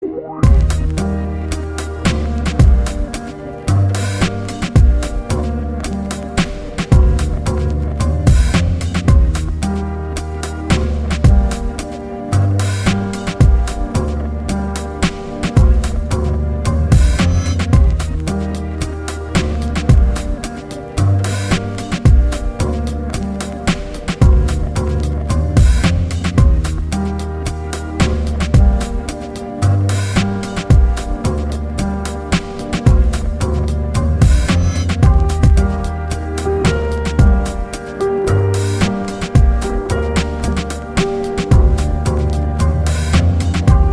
btt tt kch t kch tt t tss kch t kch tt и т. д. Не знаю чем хендклеп заменить (там звук другой какой-то); а потом не знаю как вставить еще какой-то другой постоянный хэт, который там дальше идет...